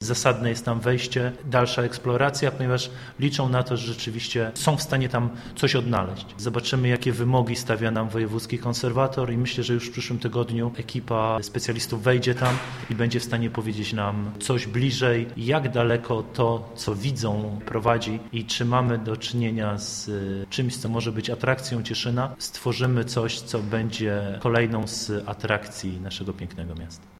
Wiceburmistrz Aleksander Cierniak - Cieszyńskie Podziemia.